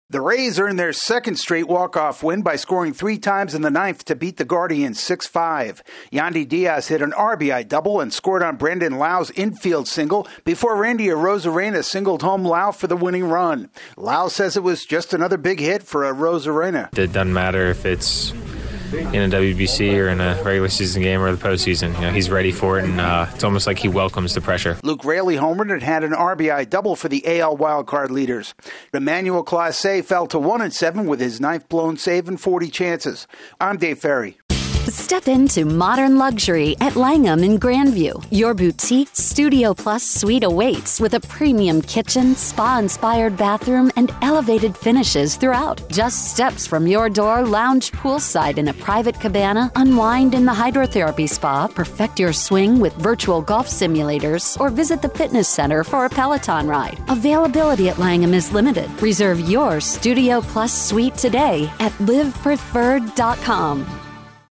The Rays pull out another win against the Guardians. AP correspondent